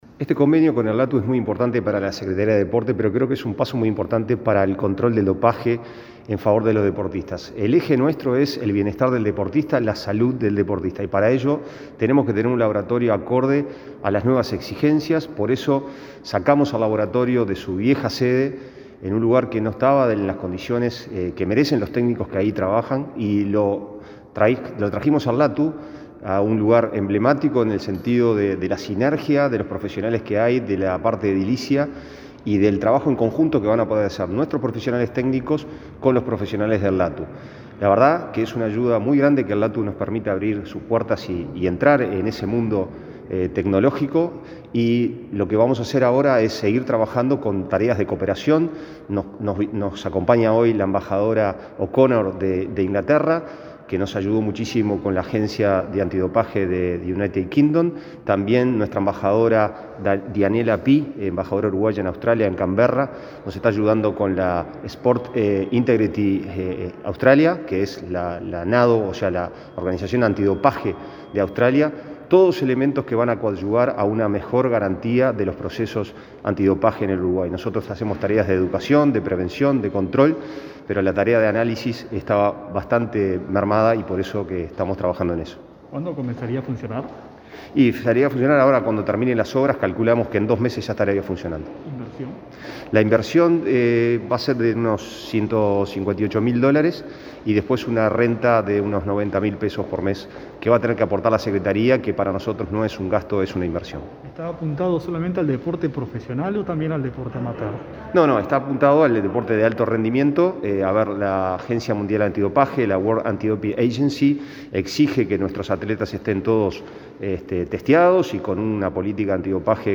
Declaraciones del subsecretario de Deporte, Pablo Ferrari, sobre acuerdo con LATU por controles antidopaje